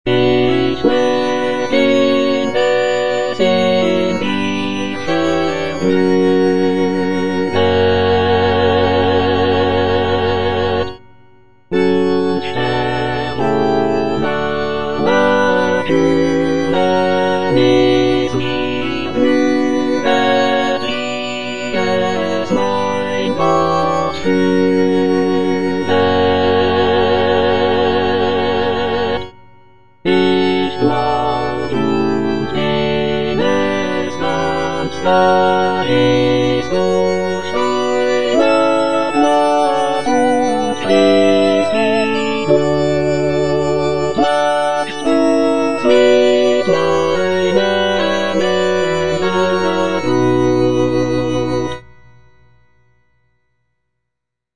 The work features a joyful and optimistic tone, with the solo soprano expressing gratitude for the blessings in her life. The text explores themes of contentment, trust in God, and the acceptance of one's fate.